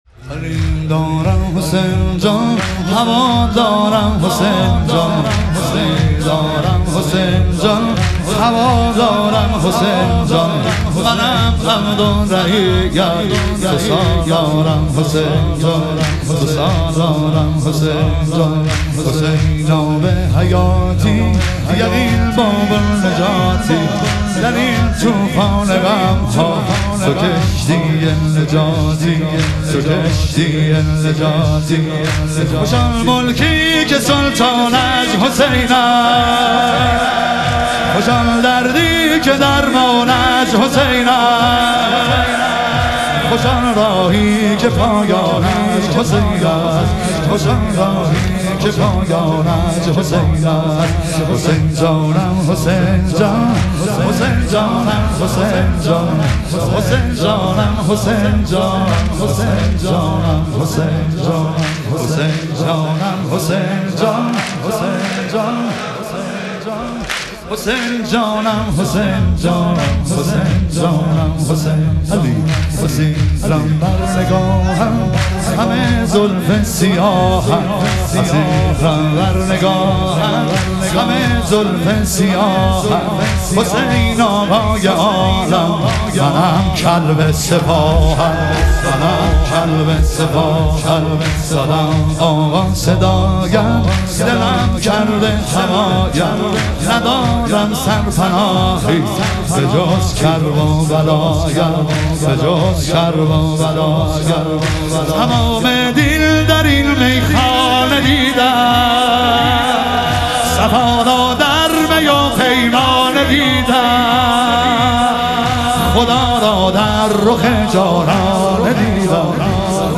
شهادت حضرت مسلم علیه السلام - واحد